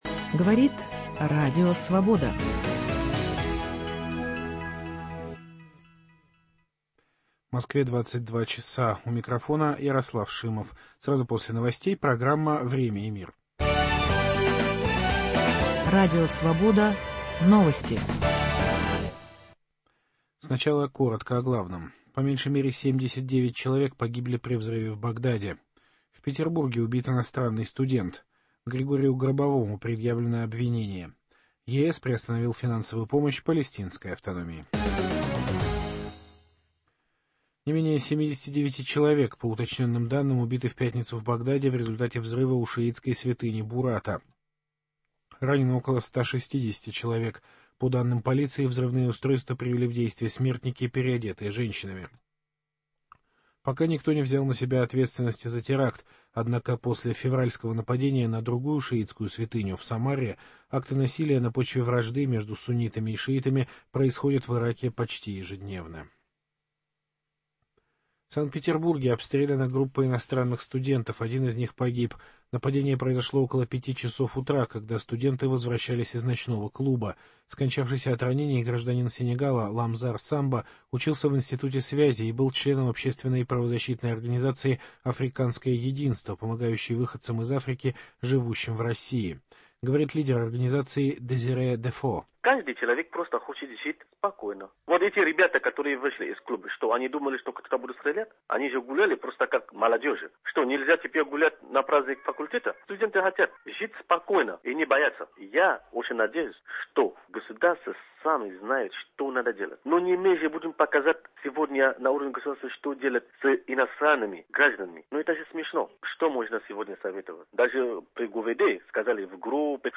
Иммиграционные законы в Италии и России. Интервью с Хамидом Карзаем. Экология: Протесты против нефтепровода по Байкалу.